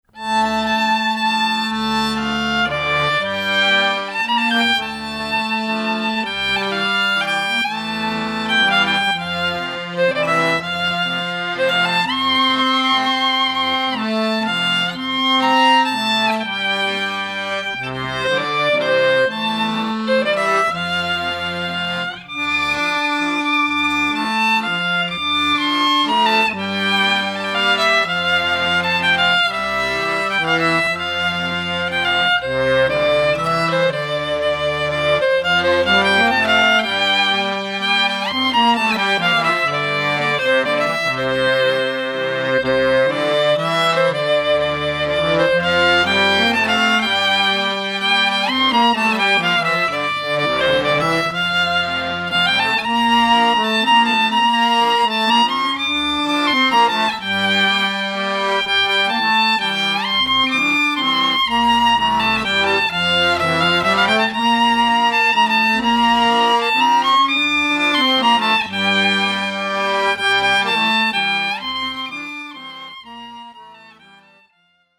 Violin
C Clarinet
Accordions, Tsimbl
Bass Cello
Genre: Klezmer.